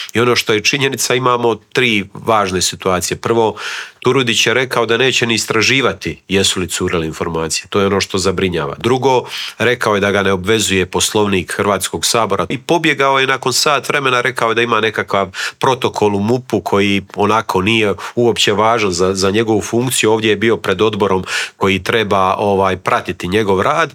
U Intervjuu Media servisa gostovao je upravo Nikola Grmoja i podijelio s nama dojmove sa sjednice.